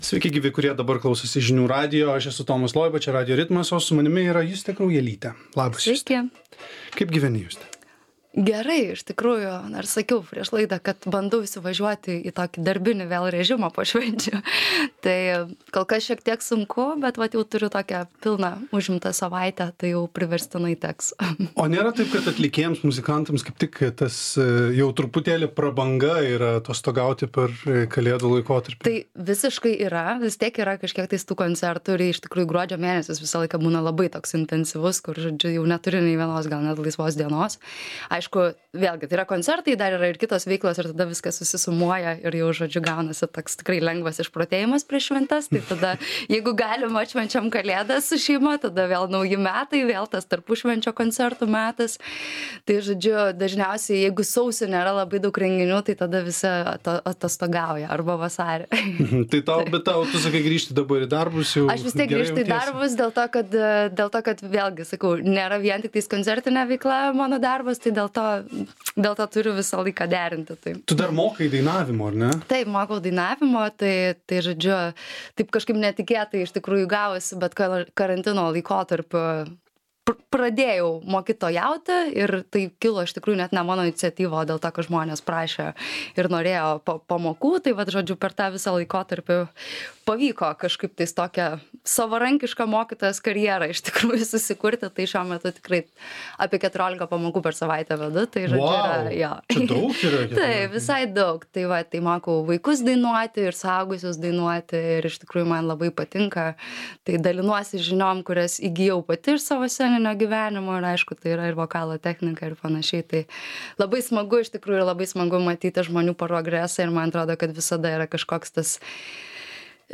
Pokalbis su atlikėja